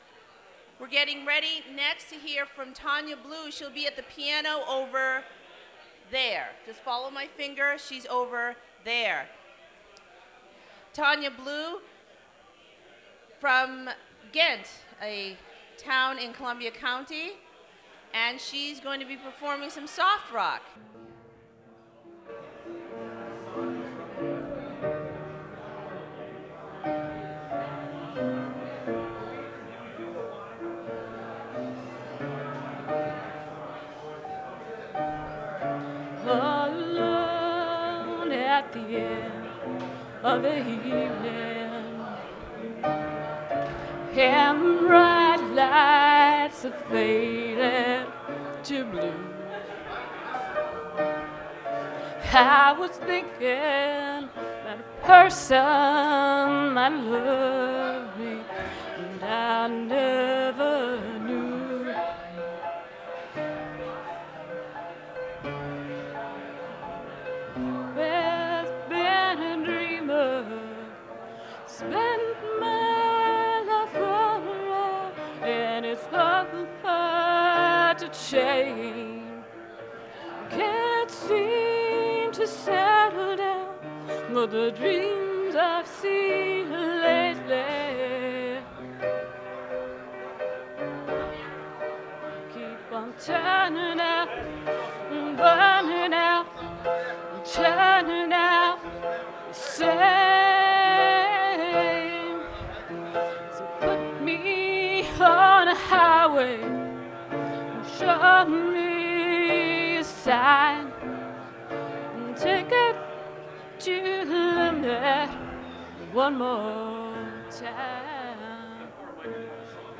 performs live
Recorded from WGXC 90.7-FM webstream.